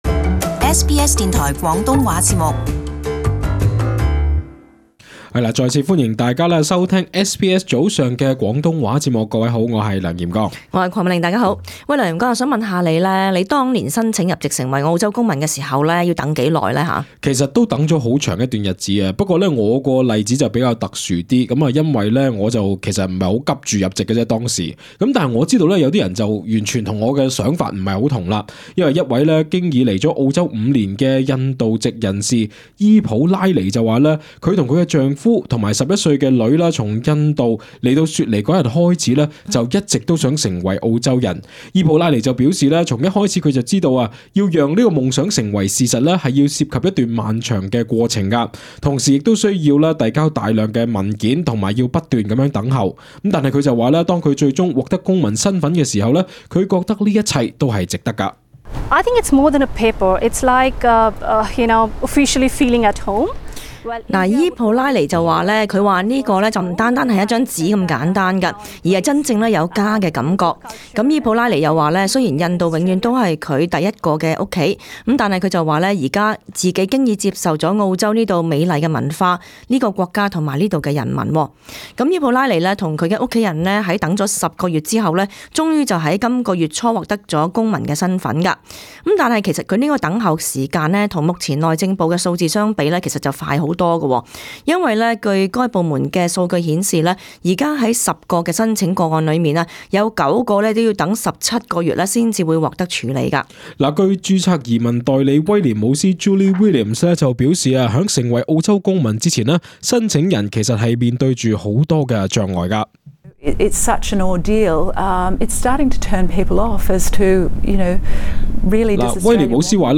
【時事報導】逾19萬入籍申請 審批需等近年半